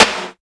99 INDUST SN.wav